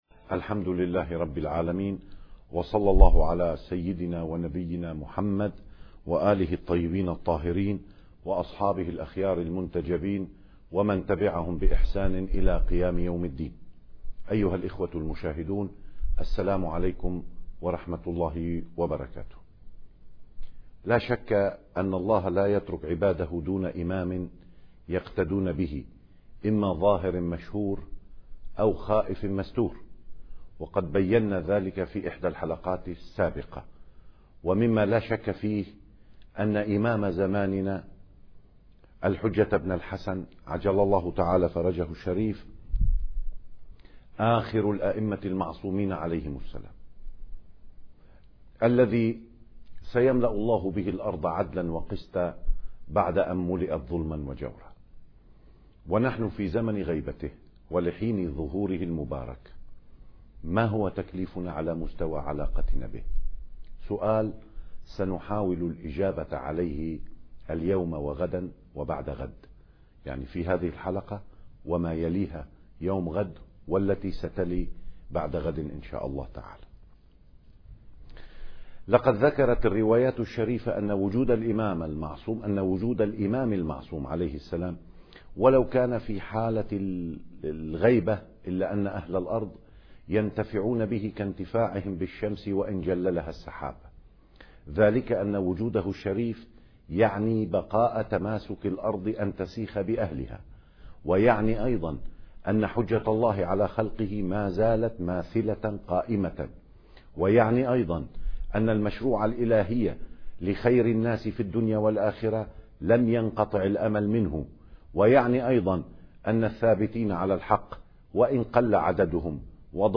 المكان: قناة كربلاء الفضائية